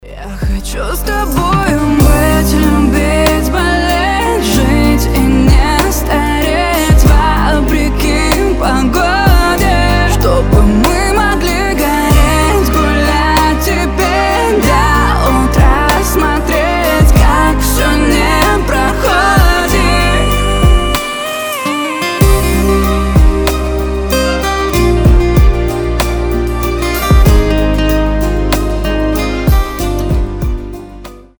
романтичные
женский голос
медленные